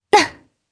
Lewsia_B-Vox_Attack1_jp.wav